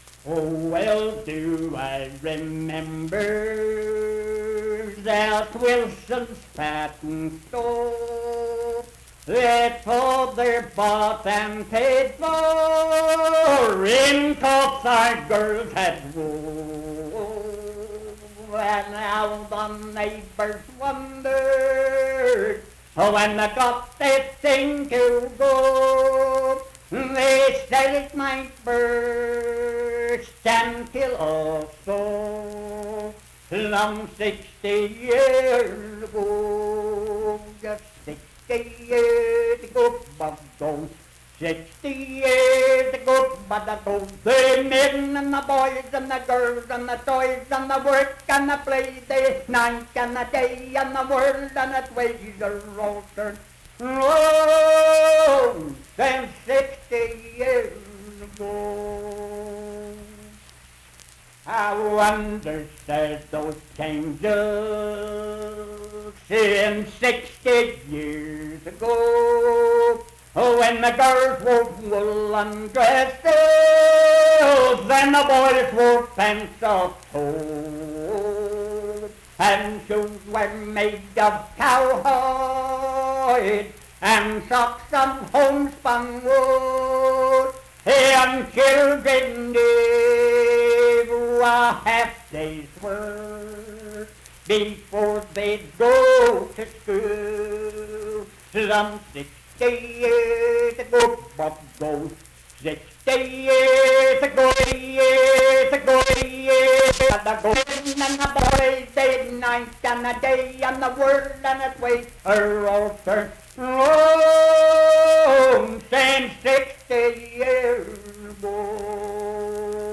Unaccompanied vocal music performance
Verse-refrain 4(8) & R(8).
Voice (sung)
Nicholas County (W. Va.)